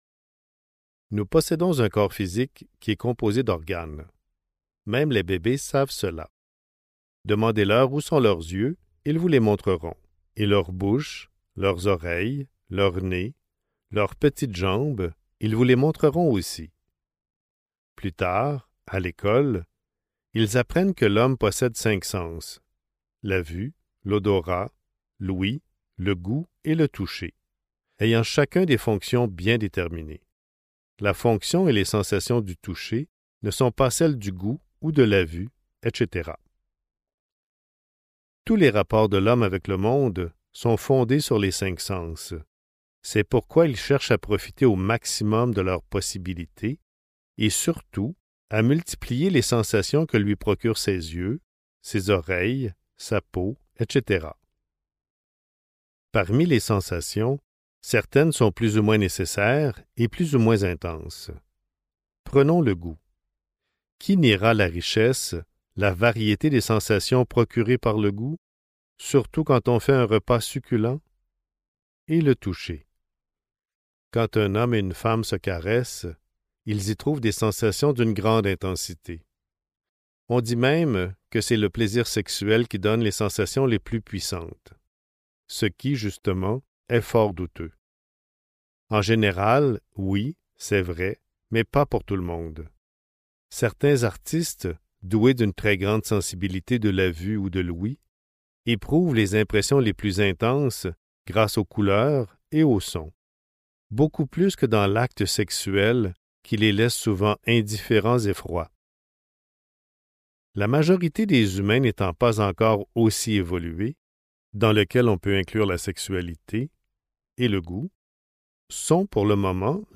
Centres et corps subtils (Livre audio | CD MP3) | Omraam Mikhaël Aïvanhov